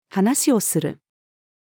話をする-female.mp3